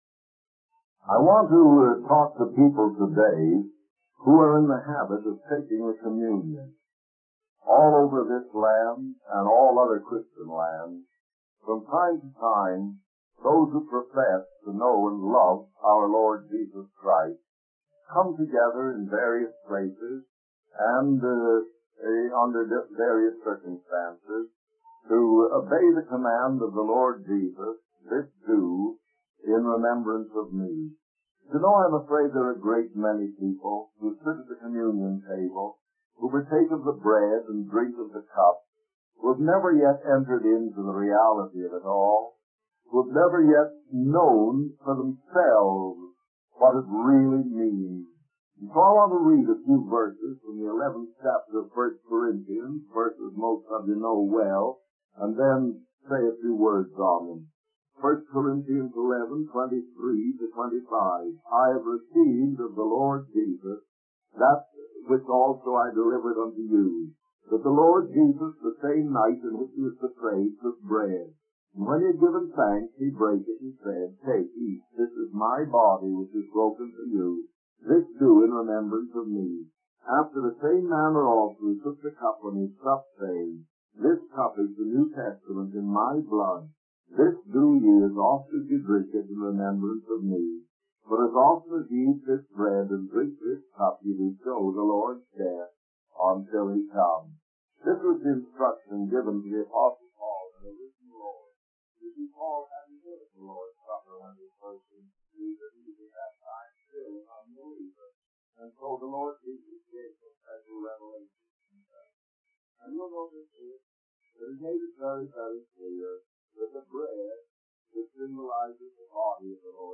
In this sermon, the preacher discusses the significance of the Lord's Supper as a perpetual memorial of the death of Christ. He emphasizes that participating in the communion without truly accepting Jesus as one's Savior is meaningless and can even lead to condemnation.